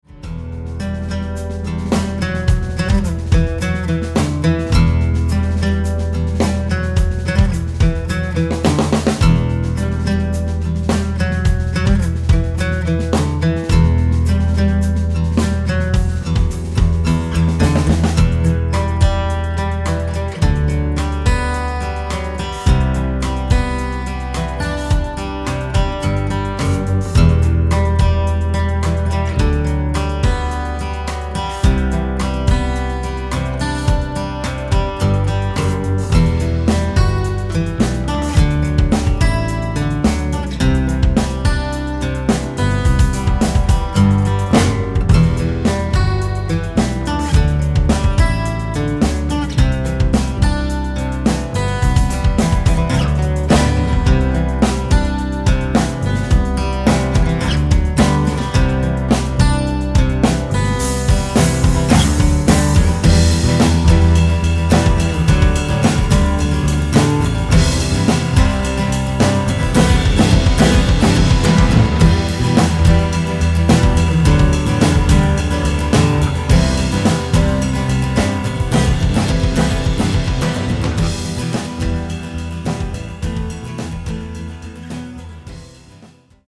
Enregistrement et Mixage